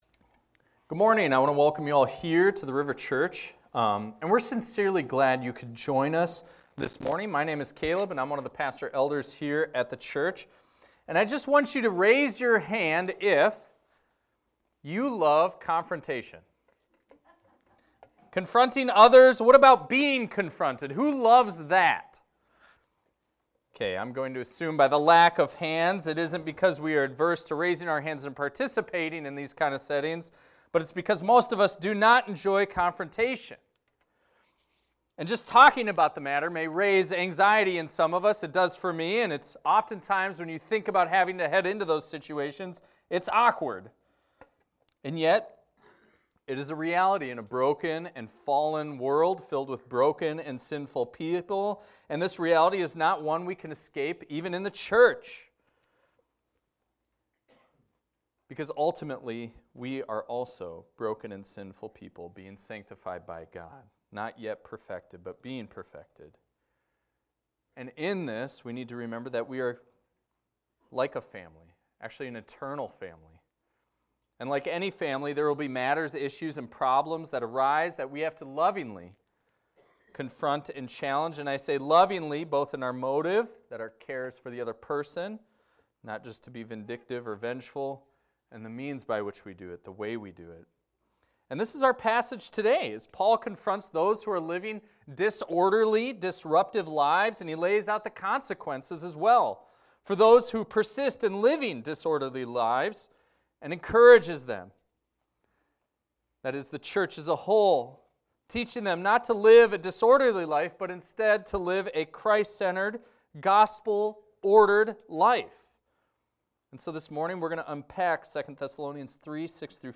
This is a recording of a sermon titled, "Good Work."